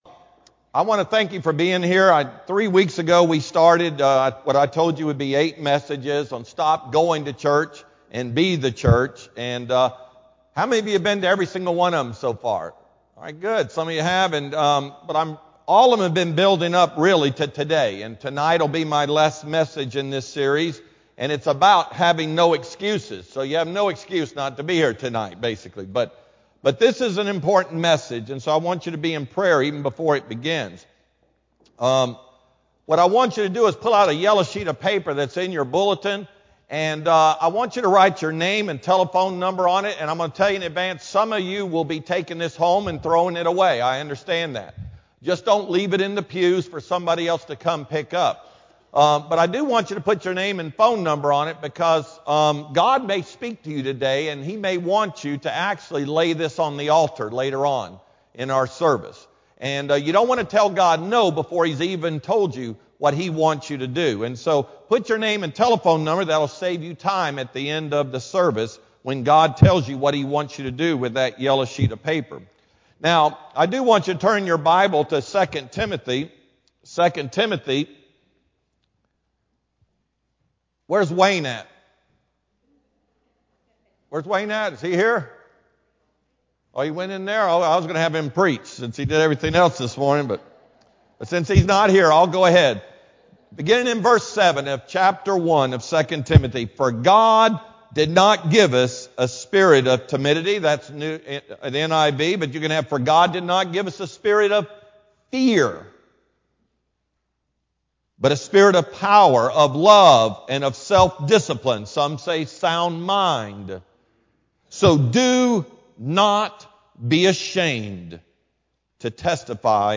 Sermon-Feb-1-2015-AM-CD.mp3